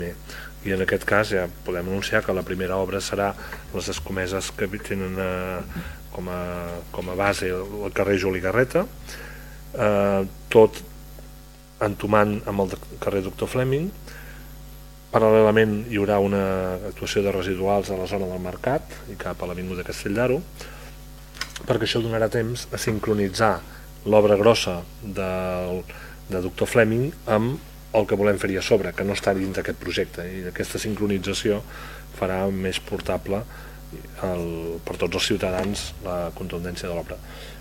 El regidor d’Urbanisme, Josep Maria Solé, va explicar les fases d’aquest projecte, que s’allargarà durant quatre anys i es convertirà en una de les grans obres que es fan al municipi: